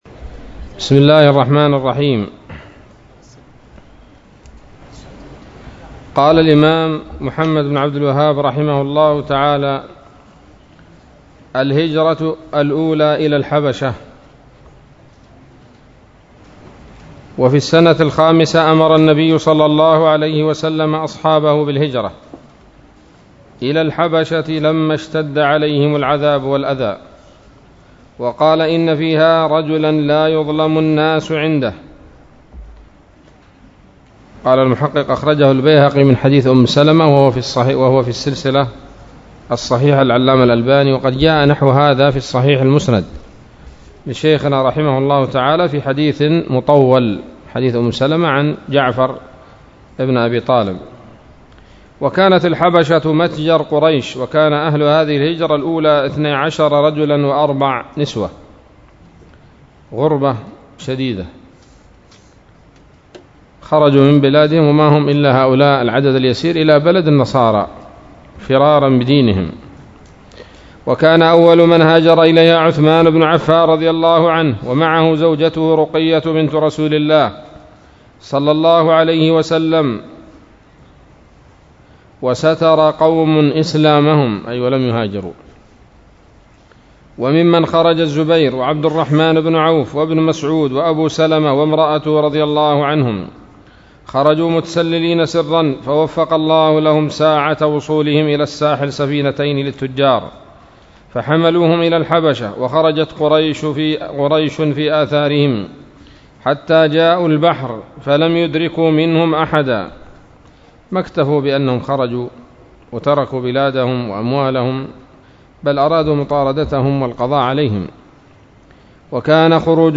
الدرس العشرون من مختصر سيرة الرسول ﷺ